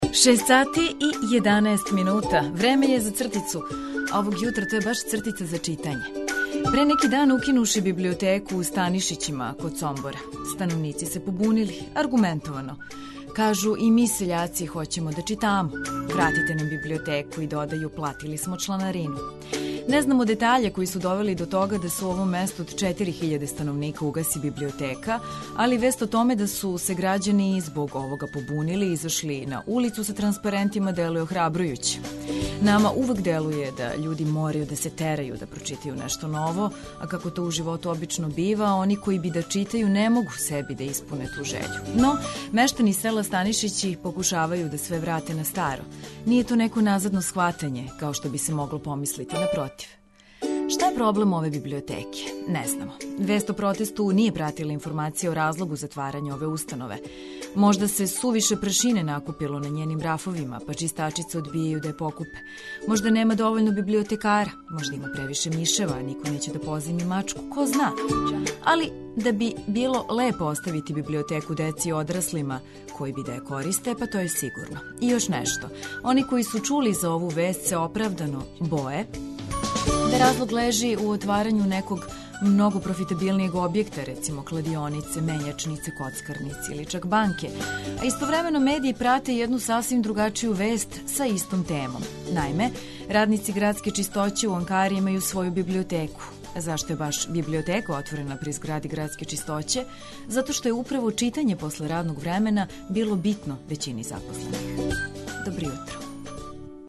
Уз различите рубрике, полетну музику и ведру екипу, биће ово право добро јутро.